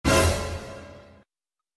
Surprise Sound Effect Free Download
Surprise